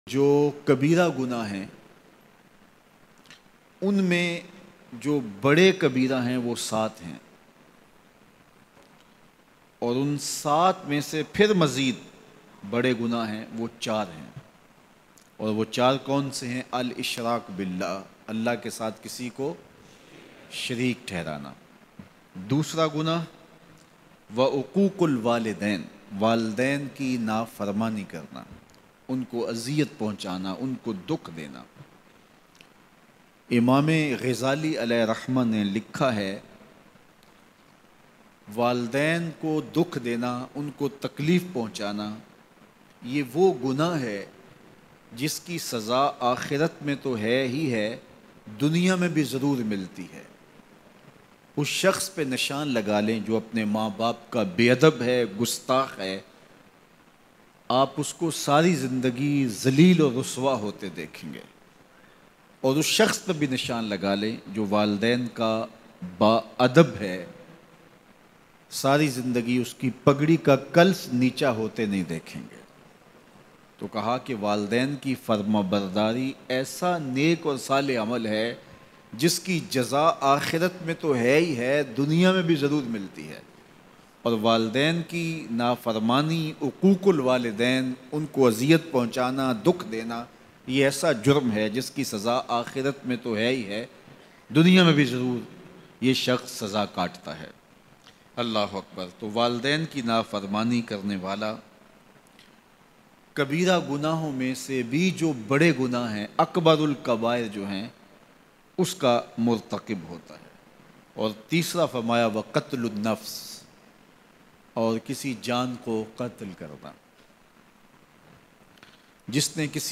Wo Neki Jis ki Jaza Dunya Me Bhi milti hai Bayan